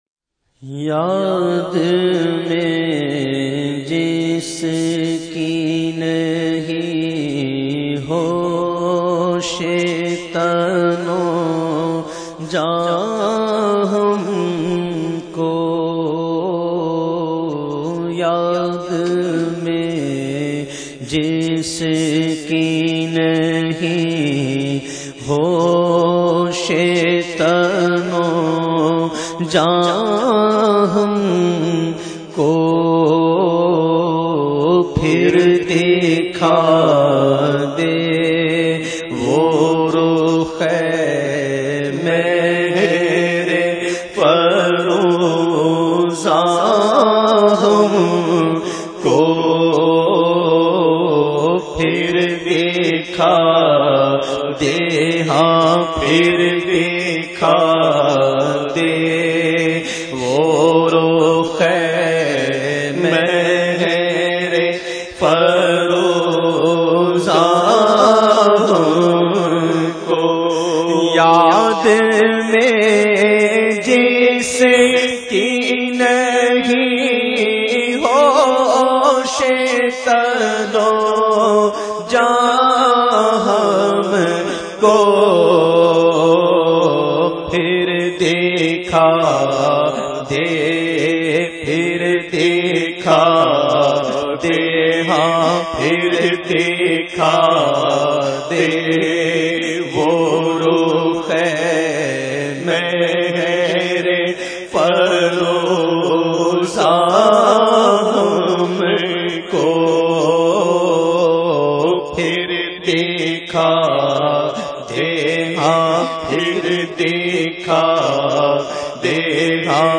The Naat Sharif Yaad Mein Jis Ki Nahi Hosh recited by famous Naat Khawan of Pakistan owaise qadri.